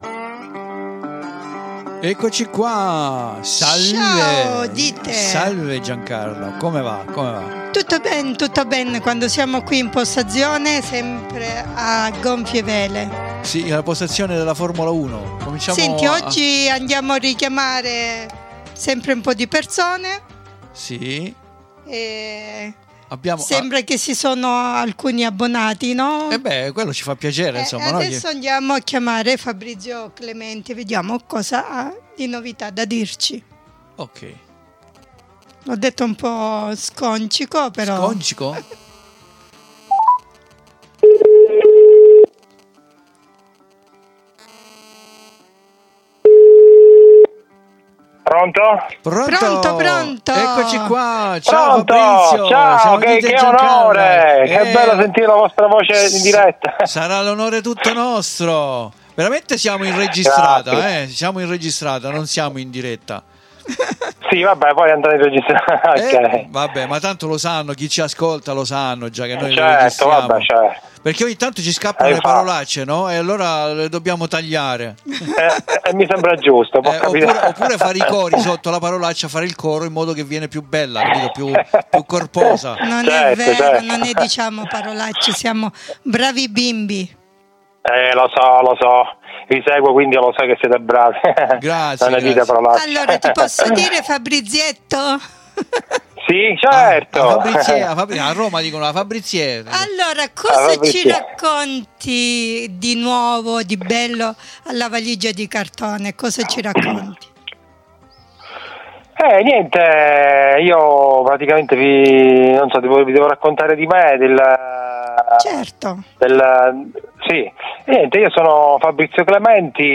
ORA NON SCRIVO AVANTI PER NON SPIFFERARE TUTTO, QUINDI VI CONSIGLIO DI ASCOLTARE IL TUTTO NELL'INTERVISTA CONDIVISA QUI IN DESCRIZIONE.